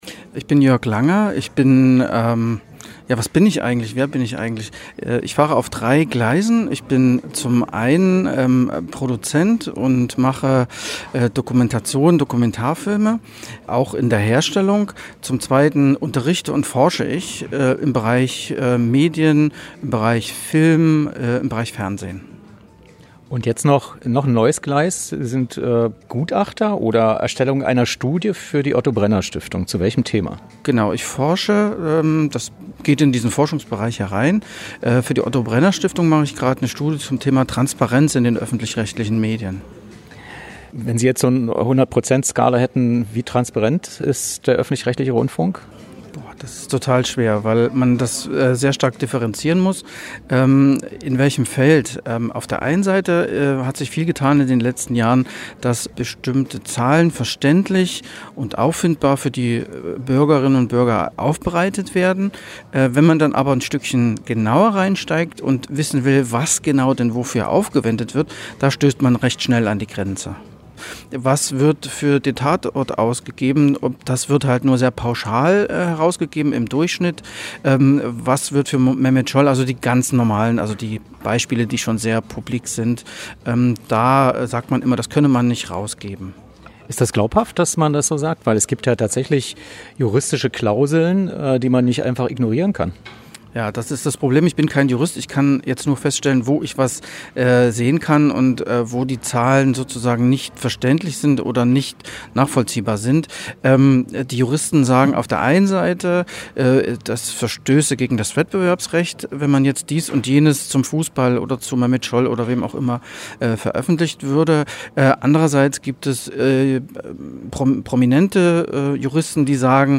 Was: Interview zur Transparenz des öffentlich-rechtlichen Rundfunks
Wo: Berlin, Heinrich-Böll-Stiftung
Kleine konstruktiv gemeinte Kritik: von einem Medienprofi erwarte ich weniger “ähm“ und “ehm“ in der Rede.